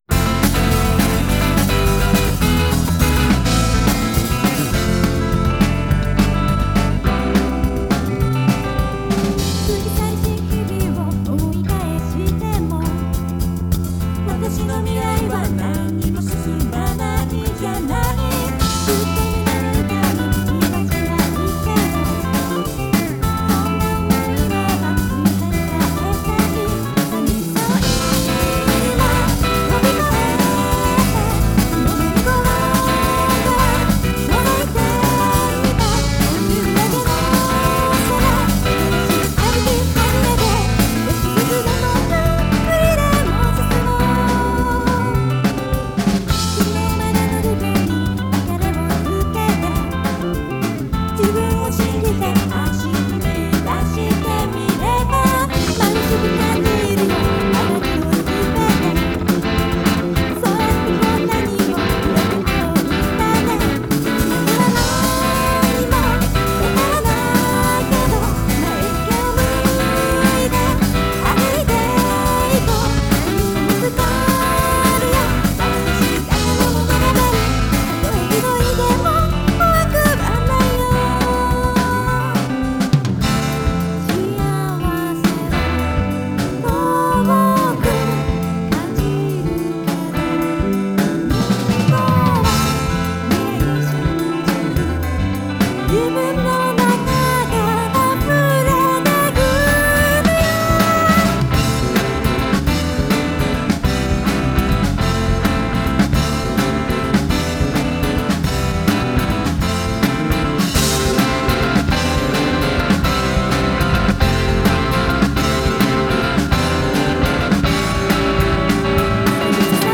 ※現在配布中の話速変換コアとは多少異なりますので、参考としてお聞き下さい。